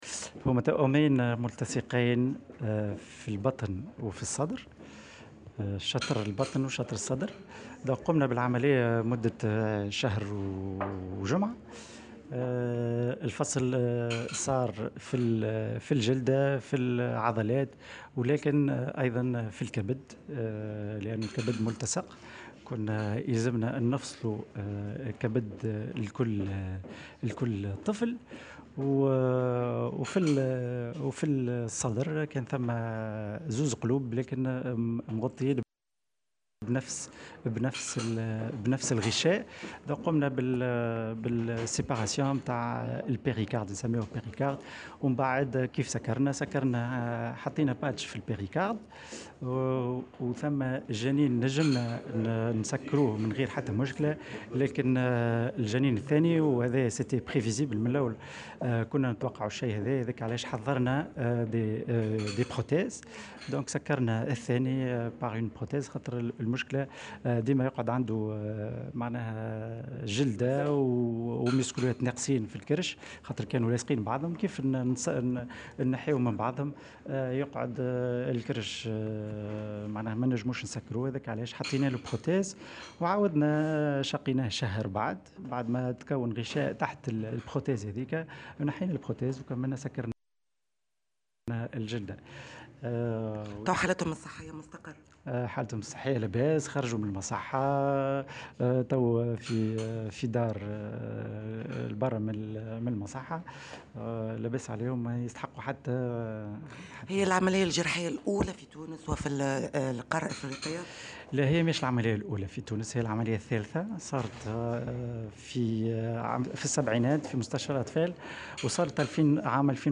في تصريح ل"الجوهرة أف أم" على هامش ندوة صحفية عقدها اليوم